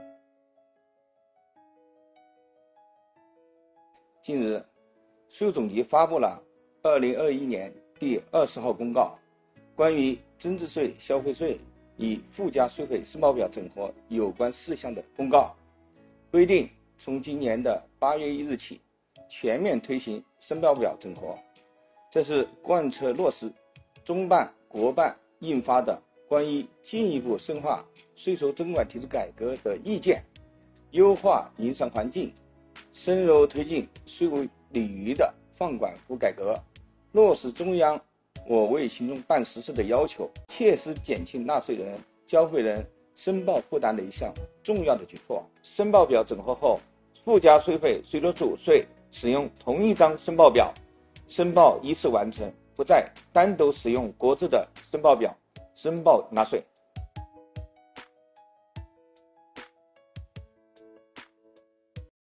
8月23日，国家税务总局推出最新一期“税务讲堂”网上公开课，税务总局货物和劳务税司副司长张卫详细解读增值税、消费税分别与附加税费申报表整合的背景意义、申报方法、主要变化和注意事项等，帮助纳税人缴费人更好了解政策、适用政策。